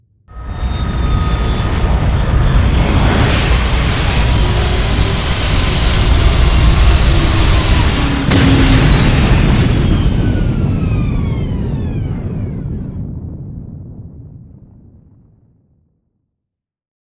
landing2.wav